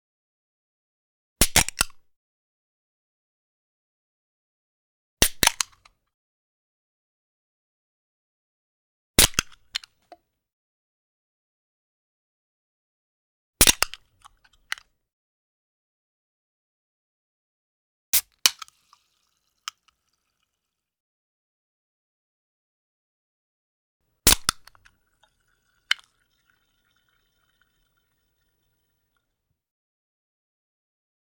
Can Soft Drink Open Sound
household
Can Soft Drink Open